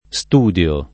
studio [